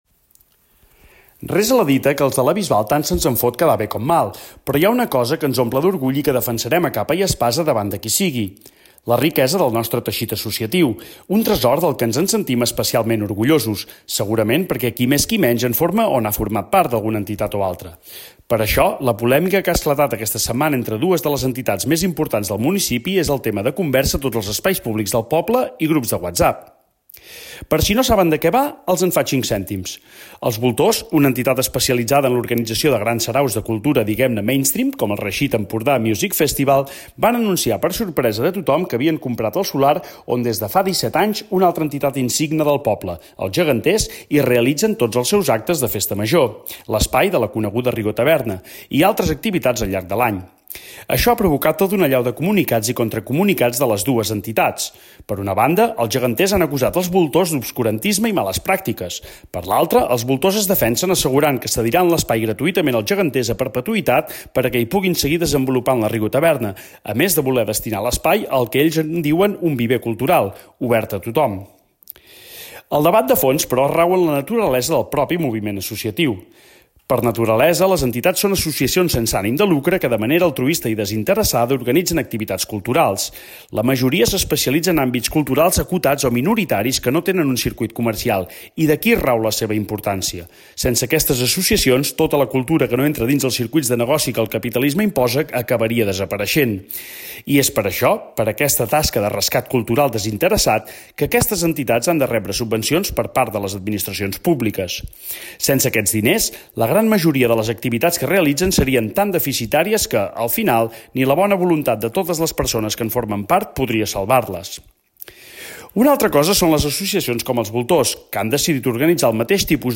amb la veu del conegut cantautor